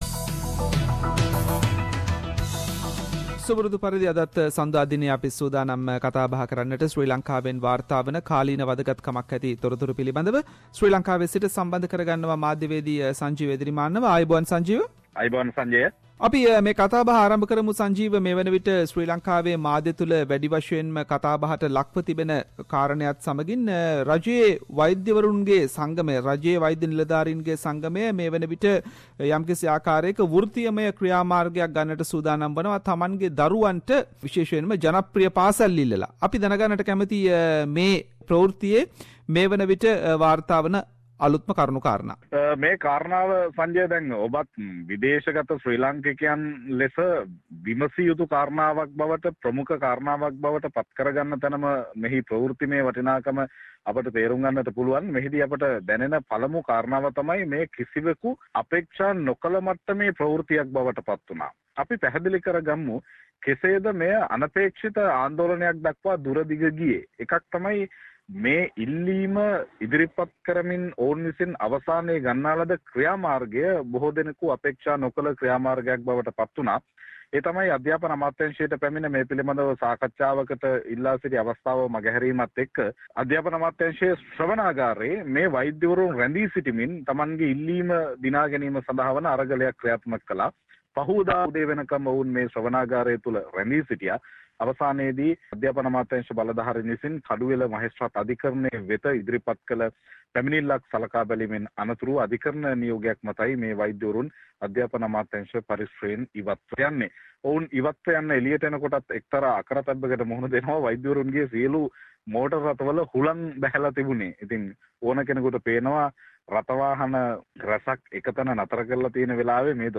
Journalist - (current affair) reports from Sri Lanka